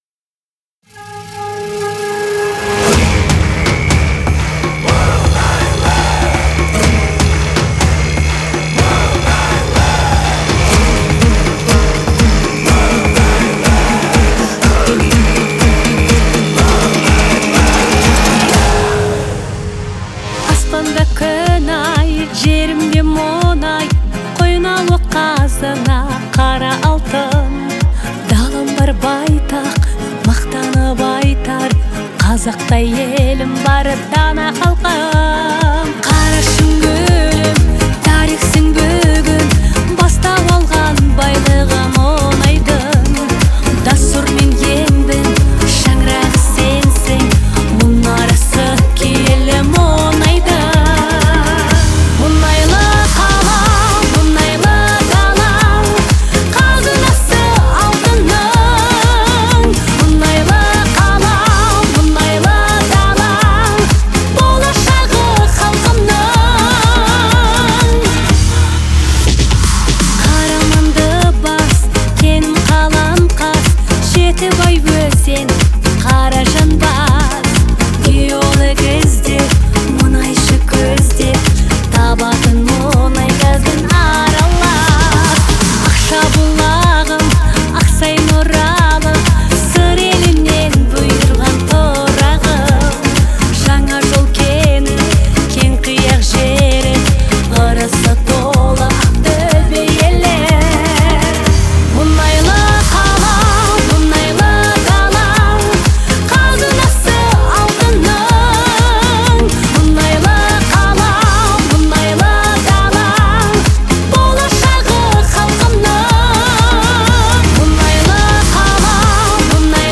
Особенностью исполнения является мощный голос